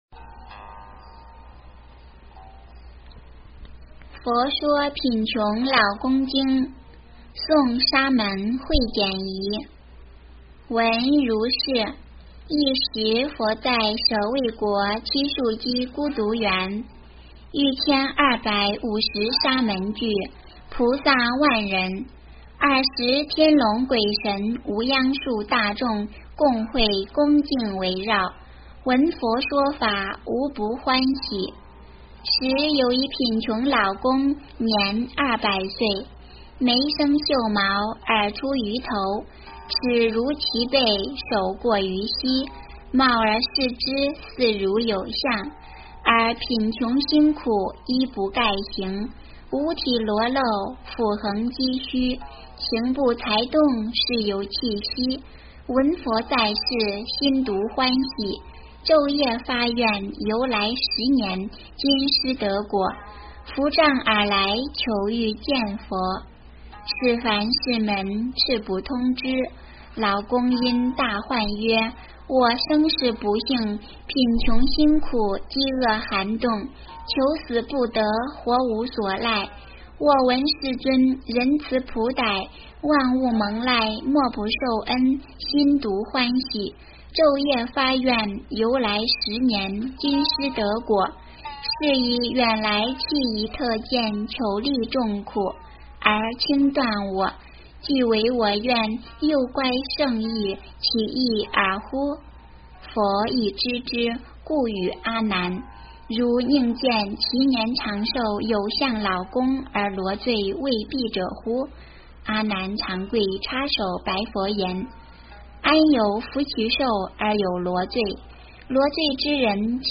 佛说贫穷老公经 - 诵经 - 云佛论坛